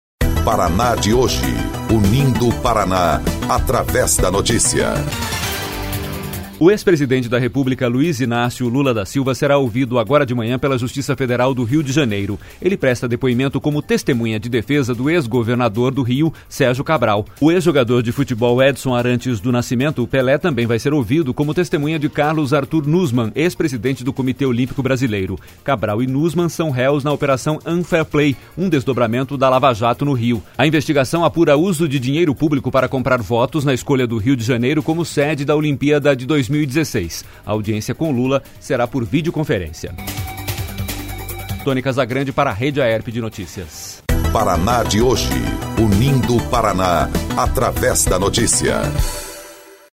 05.05-Boletim-com-trilha-Lula-vai-ser-ouvido-por-videoconferência.mp3